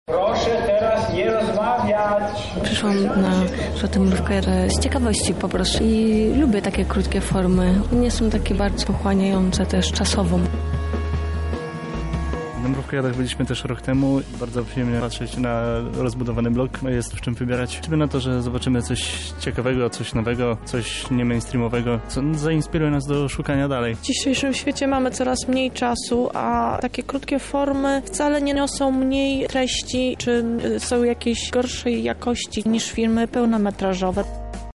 Zapytaliśmy, co o tych drugich myślą widzowie.